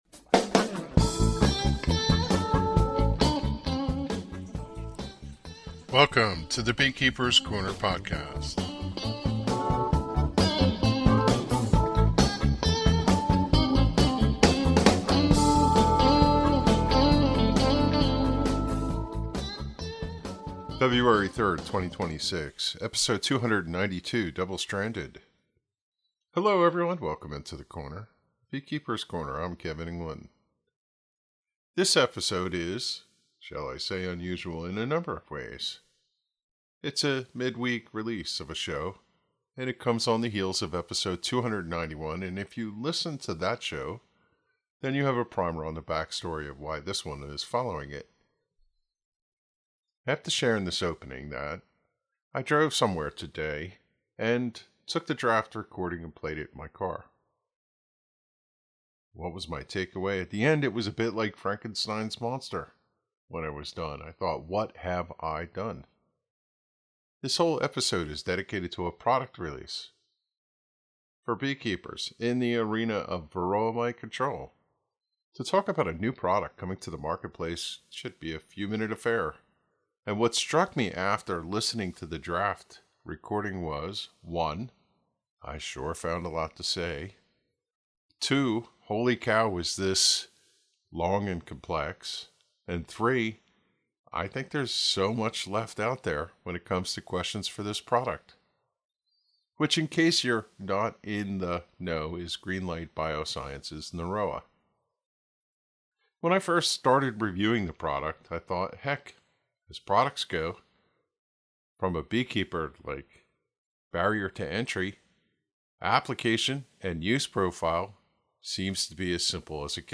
Opening Music